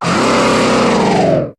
Cri de Sarmuraï dans Pokémon HOME.